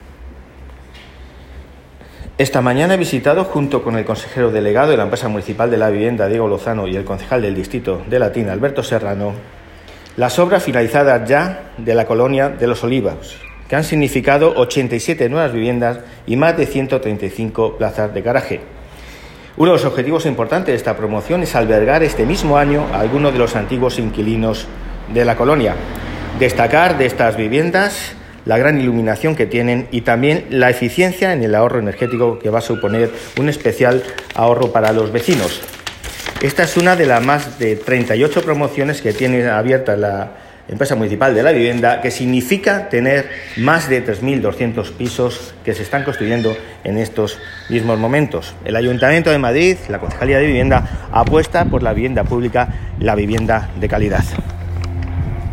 Nueva ventana:Declaraciones de Álvaro González López, concejal delegado de Vivienda